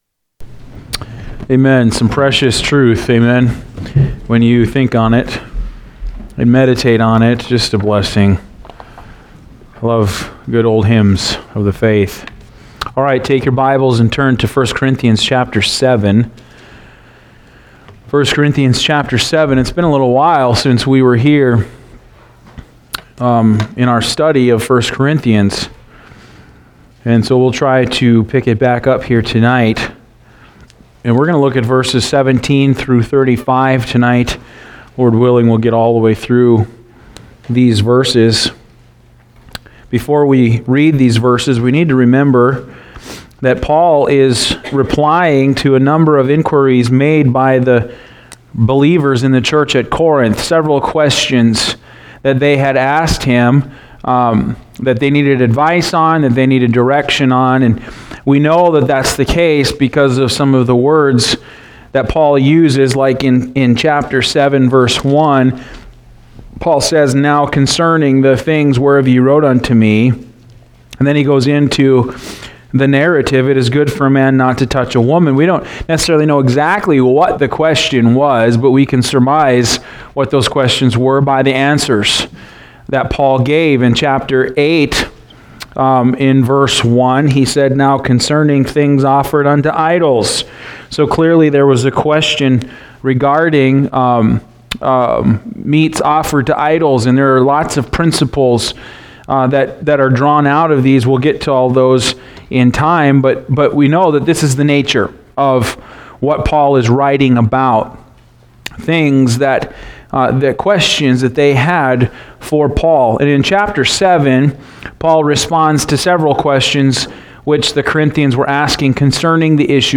Passage: I Corinthians 7:10-35 Service Type: Wednesday Evening